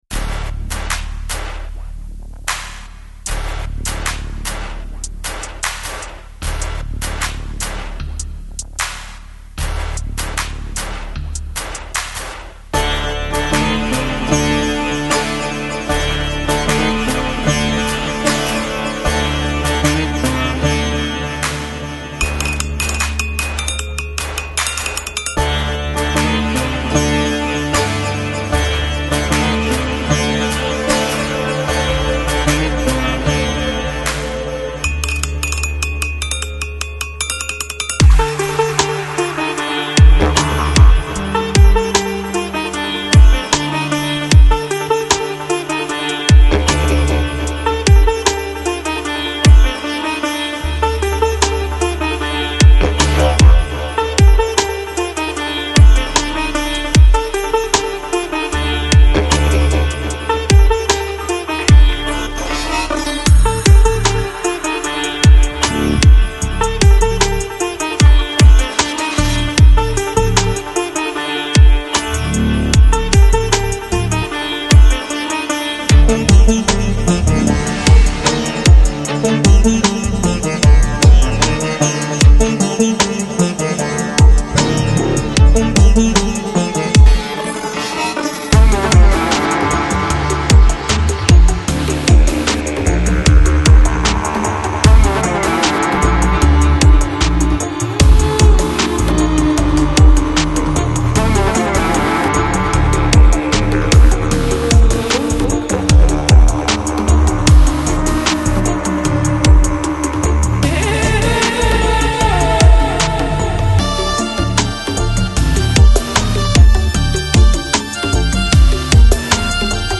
Жанр: PsyDub, Psybient,